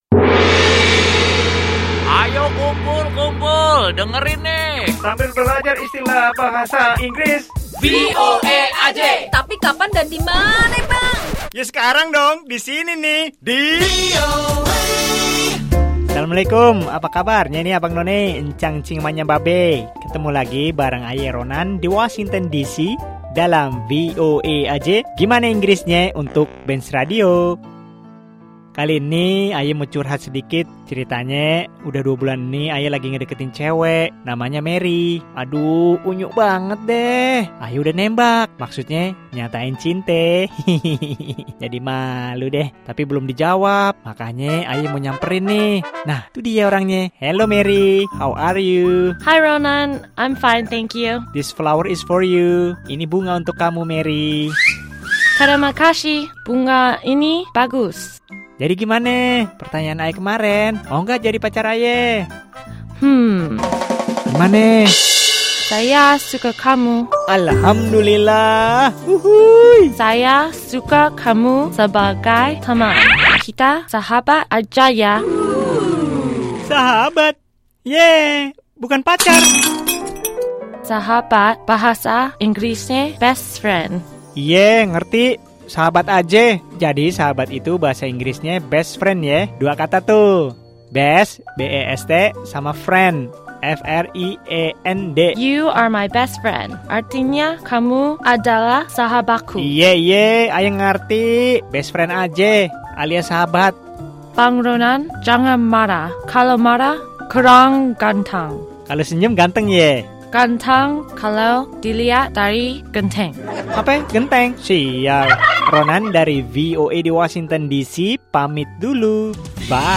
Simak percakapan tentang arti dan pengucapan kata Best Friend yang artinya Sahabat.